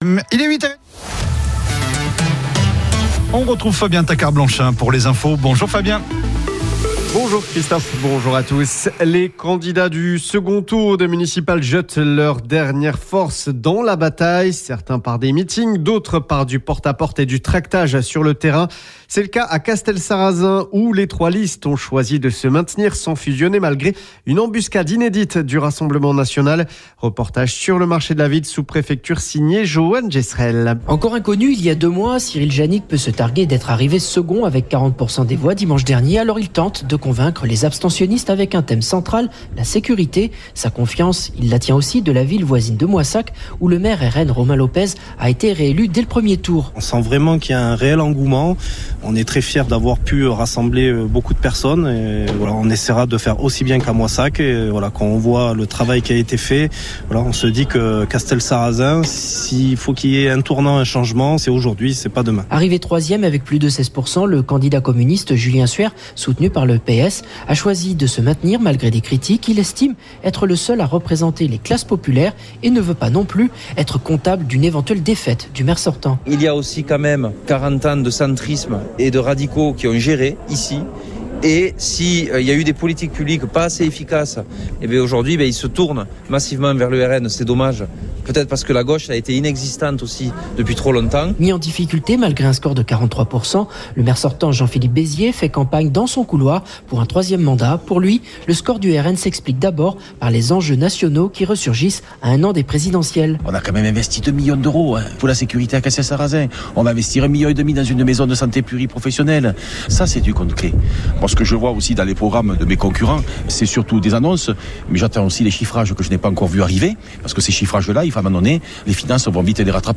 Sur le marché de la ville chacun défend sa stratégie dans un contexte marqué par la poussée inédite du RN dans la ville sous-préfecture du Tarn-et-Garonne.